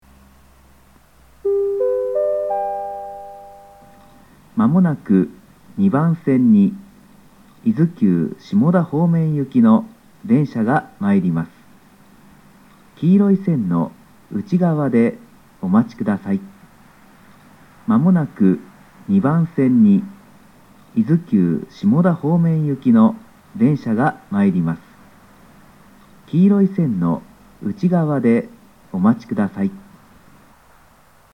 （男性）
1番線を上り特急が通過する際の交換列車で聞けます。
下り接近放送